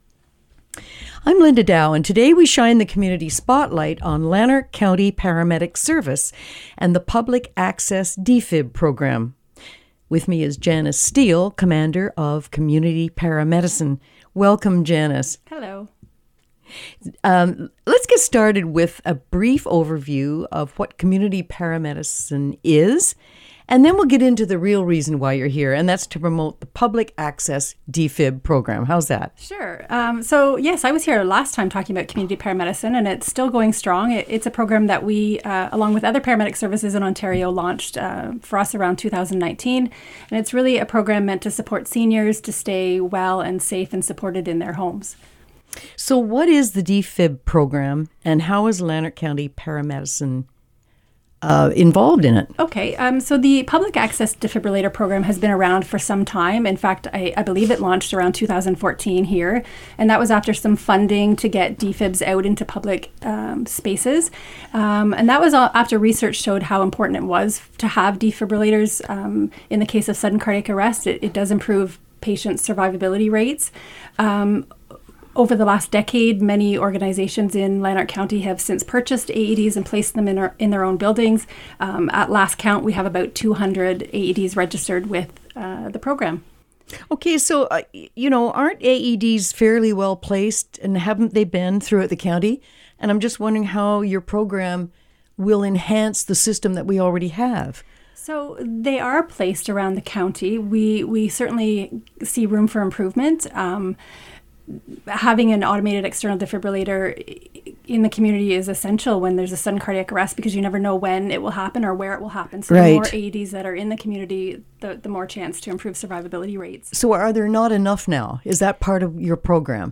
Defibrillators – dee-fib-rill-ators